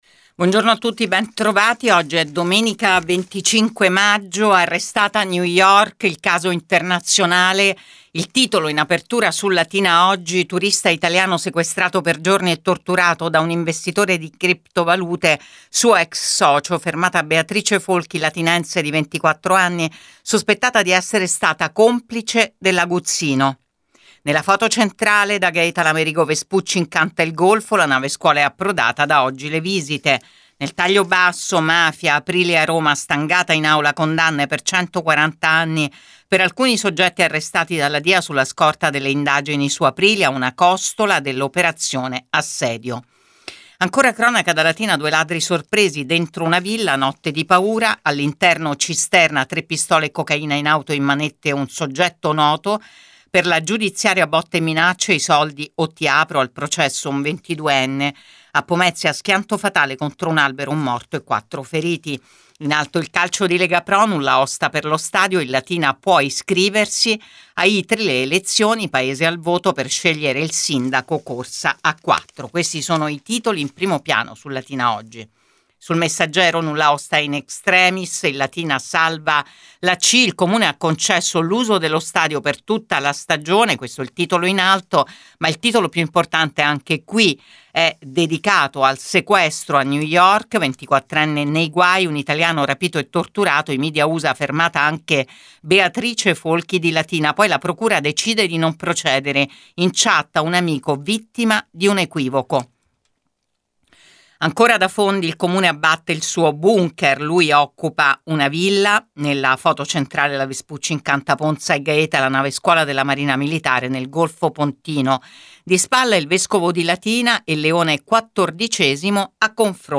LATINA – Qui trovate Prima Pagina, in un file audio di pochi minuti, i titoli di Latina Editoriale Oggi e del Messaggero Latina.